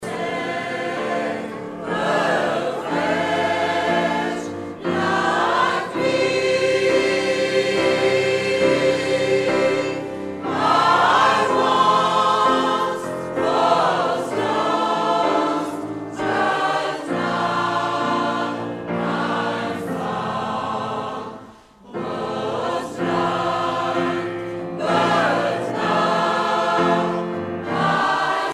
Edition discographique Live
Pièce musicale éditée